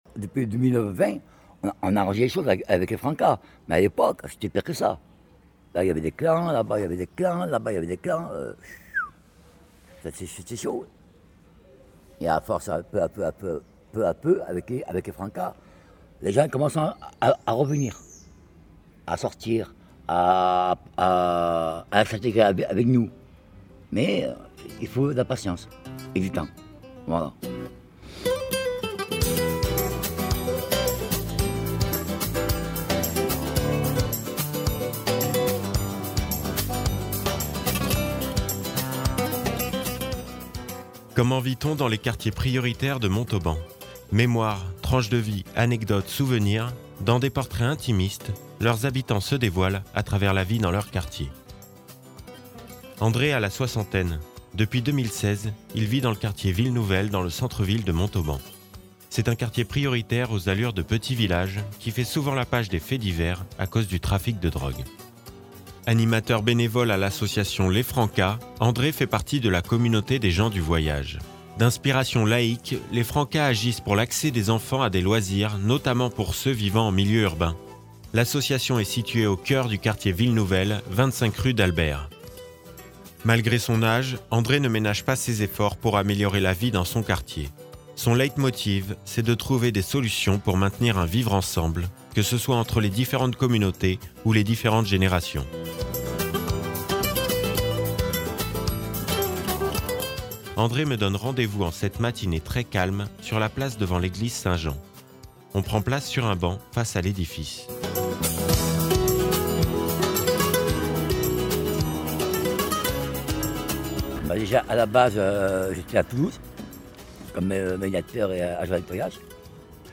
On prend place sur un banc face à l’édifice.